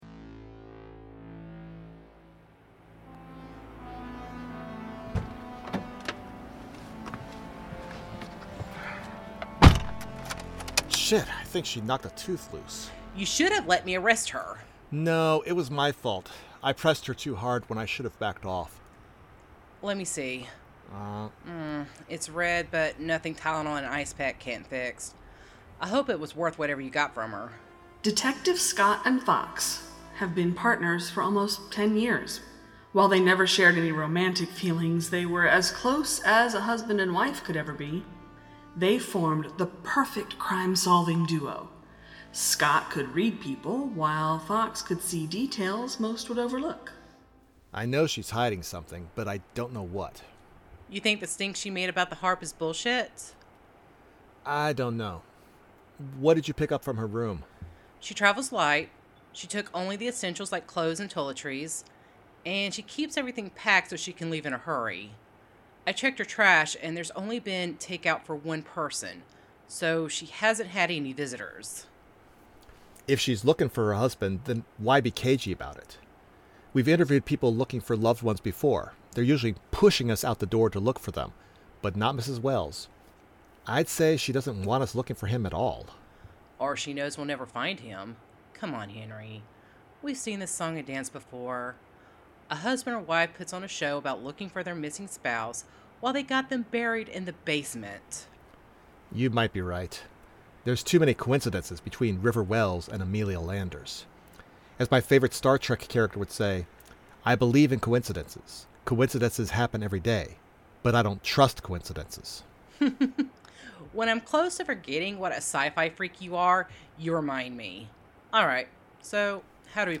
best audio drama